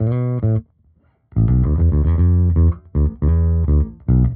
Index of /musicradar/dusty-funk-samples/Bass/110bpm
DF_JaBass_110-B.wav